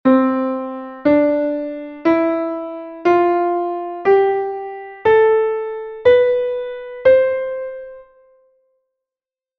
escala_do.mp3